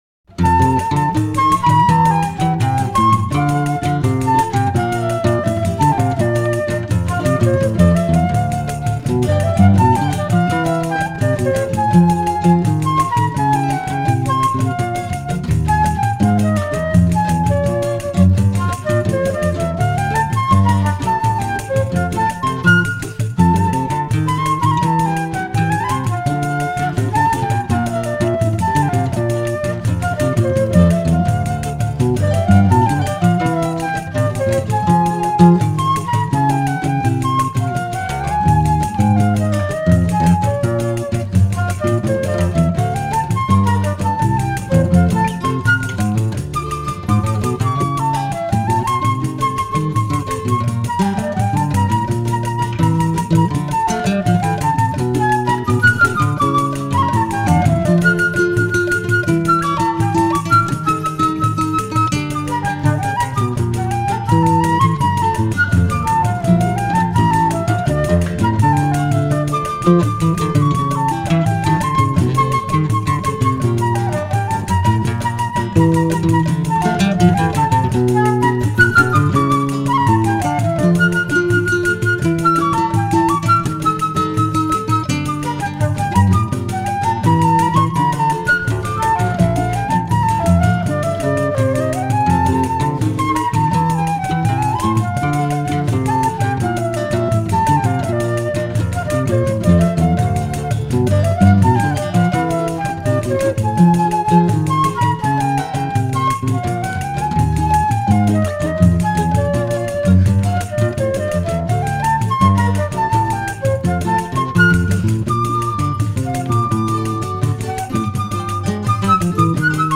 Chorinho